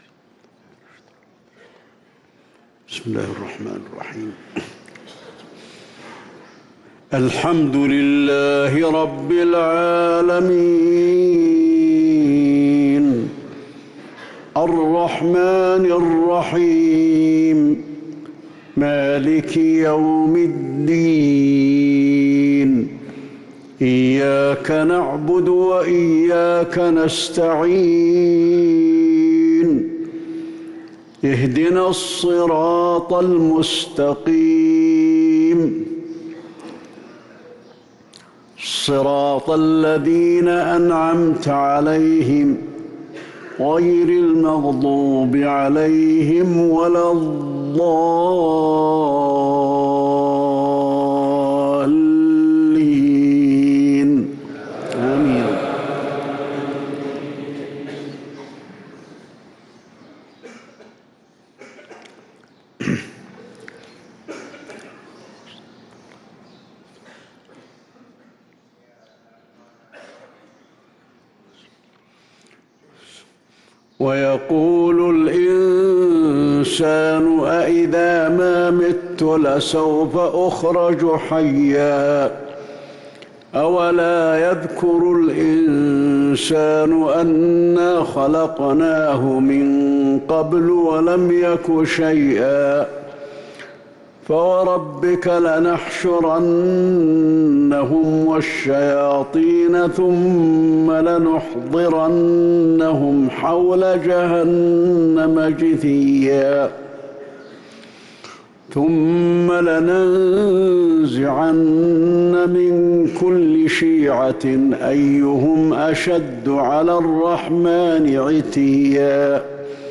صلاة المغرب للقارئ علي الحذيفي 4 شوال 1444 هـ